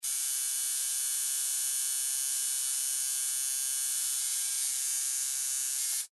На этой странице собраны звуки работы тату-машинки в разных режимах: от мягкого жужжания до интенсивного гудения.
Звук тату машинки: Особенные звуки татуировочного аппарата!